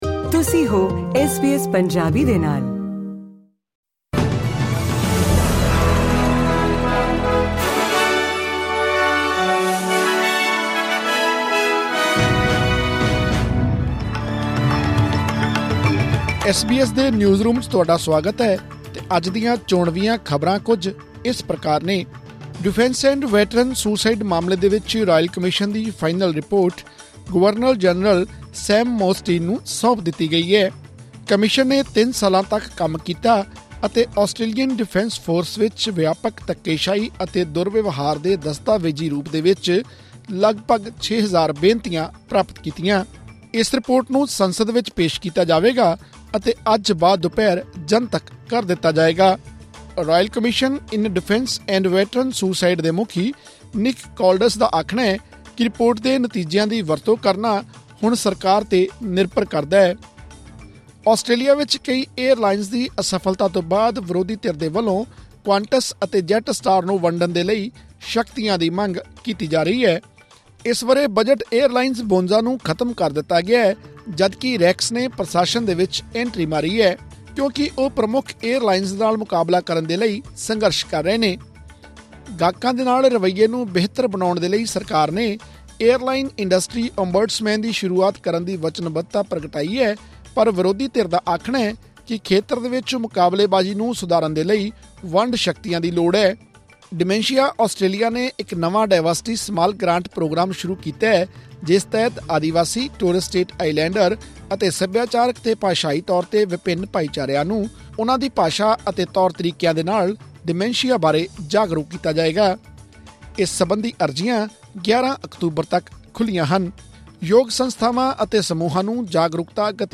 ਐਸ ਬੀ ਐਸ ਪੰਜਾਬੀ ਤੋਂ ਆਸਟ੍ਰੇਲੀਆ ਦੀਆਂ ਮੁੱਖ ਖ਼ਬਰਾਂ: 9 ਸਤੰਬਰ 2024